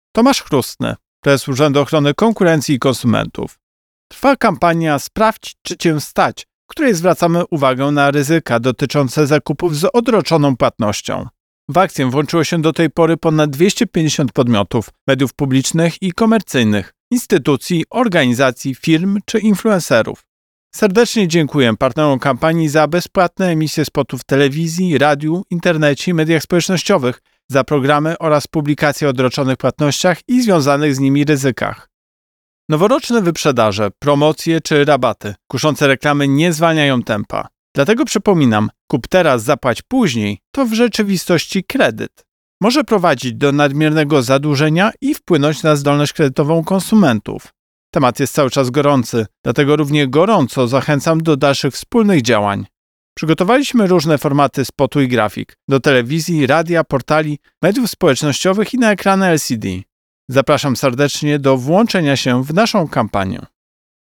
Wypowiedź Prezesa UOKiK Tomasza Chróstnego z 16 stycznia 2025 r..mp3